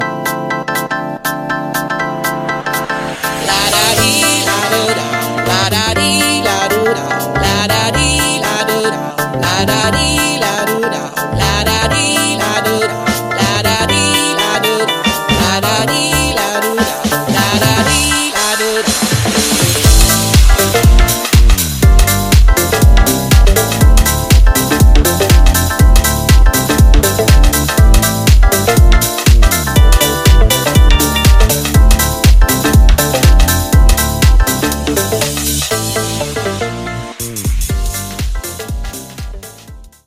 Genres: 90's , EDM , TECH HOUSE , TOP40
Clean BPM: 121 Time